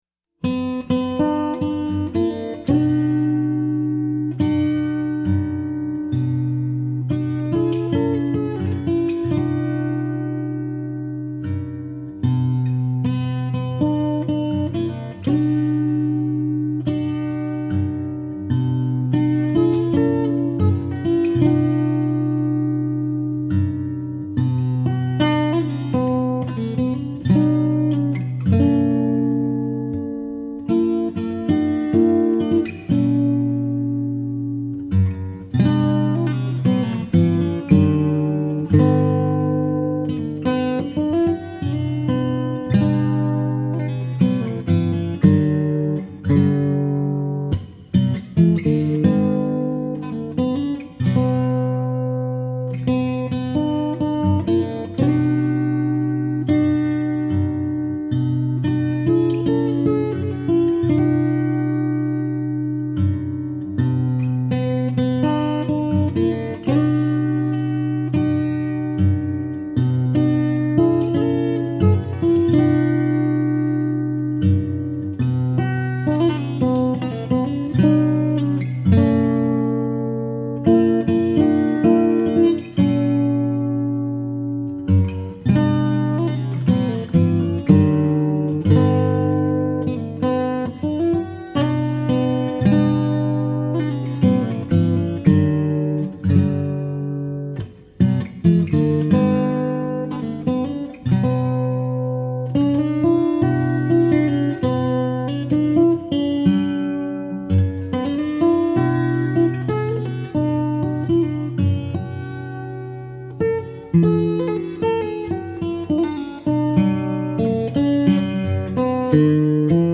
FINGERPICKING SOLO Christmas
Guitar Solos